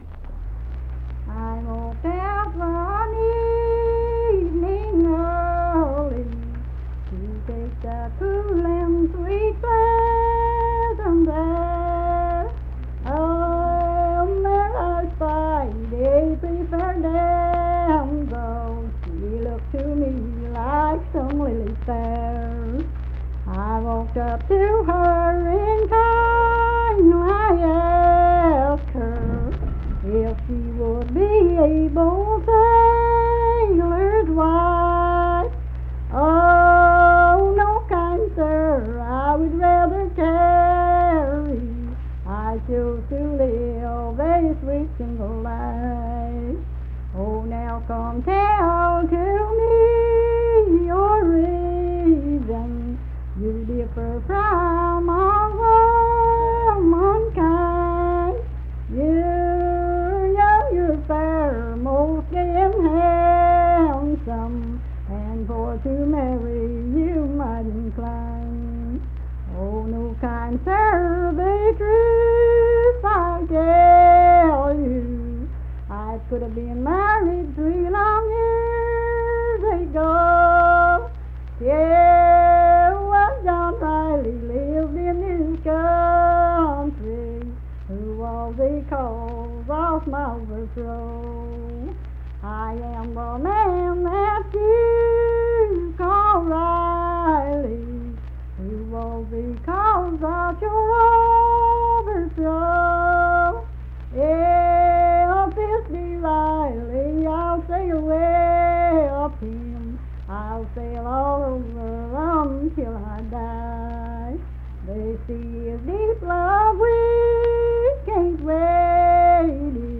Unaccompanied vocal music
Verse-refrain, 6(4).
Voice (sung)